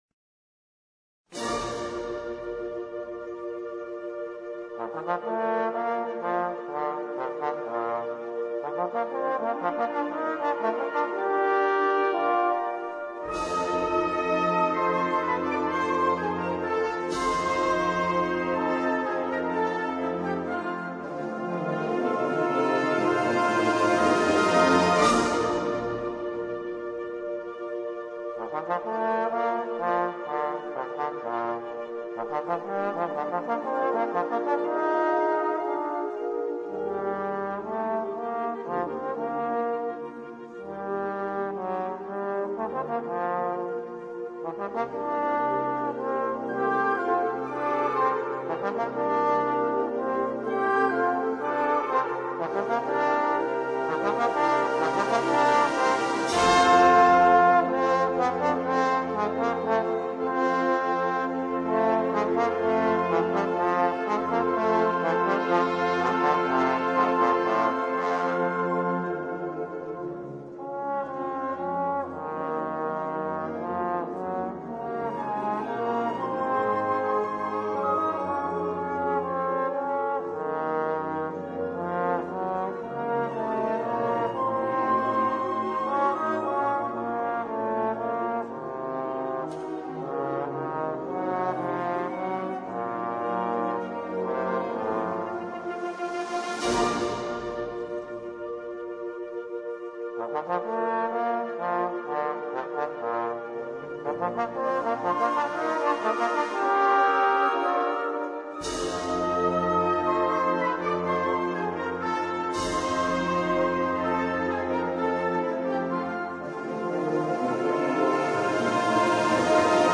CON SOLISTA